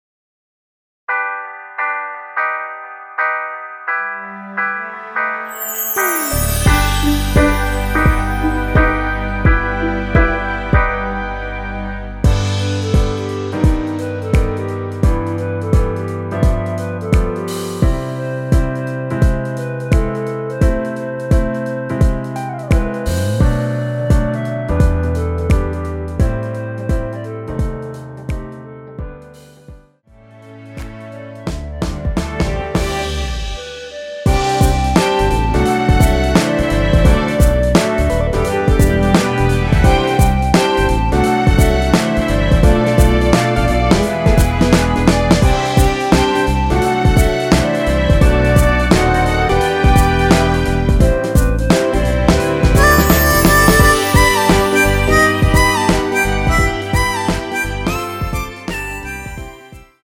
엔딩이 페이드 아웃이라서 노래하기 편하게 엔딩을 만들어 놓았으니 코러스 MR 미리듣기 확인하여주세요!
원키에서(-1)내린 멜로디 포함된 MR입니다.
앞부분30초, 뒷부분30초씩 편집해서 올려 드리고 있습니다.